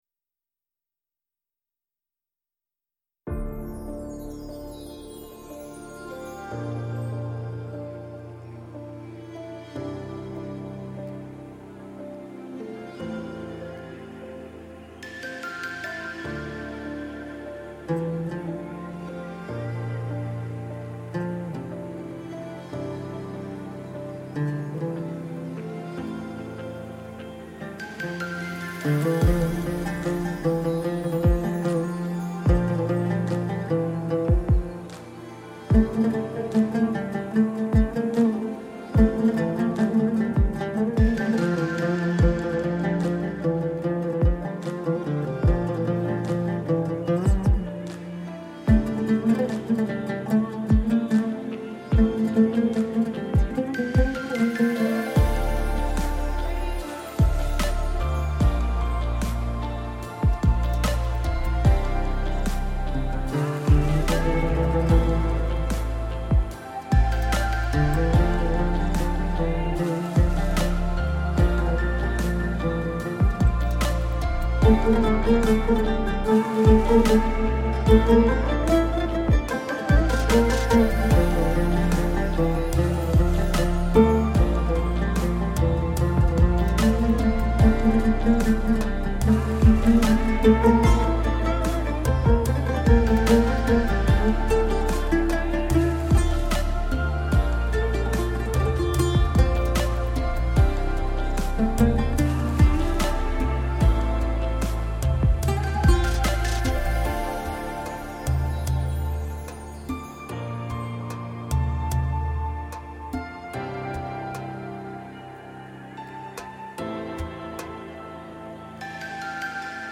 Audio Branding Elements
• Music Theme OUD 00:00